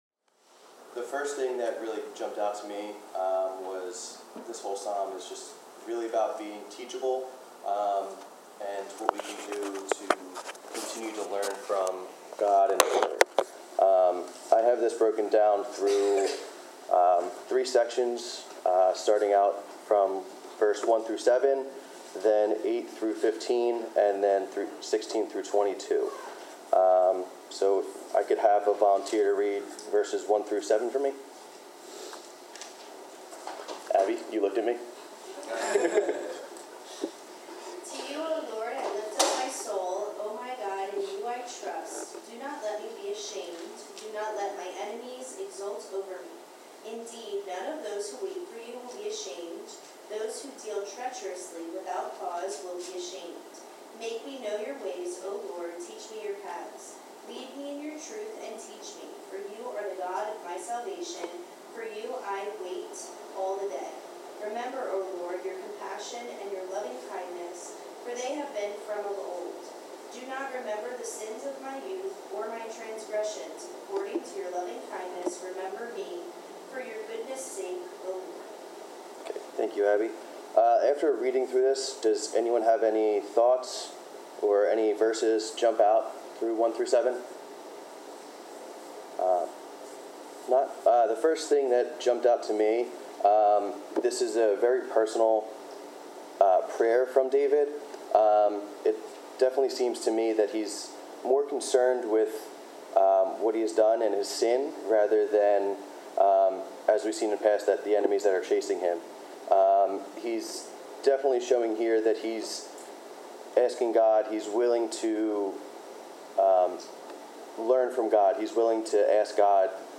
Bible class: Psalm 25
Service Type: Bible Class Topics: Bible , Faith , Fearing God , Forgiveness , Humility , Jesus , Mercy , Patience , Trusting in God « Being Single and in Christ Bible class